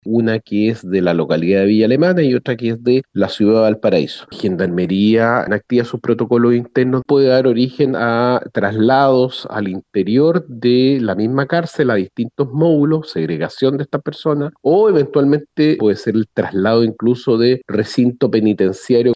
Desde la Defensoría Penal Pública relevaron al interior del penal hay una disputa de bandas rivales, agrupadas en reos que tenían residencia en Valparaíso y otros en Villa Alemana, detalló Claudio Pérez, defensor penal regional.